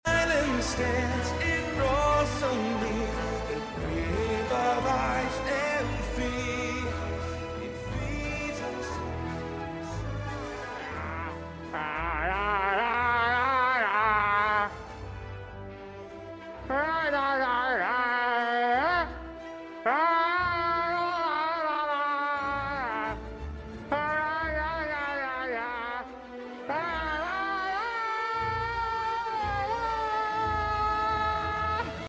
Every wondered how the voice actually sounds in recording studios?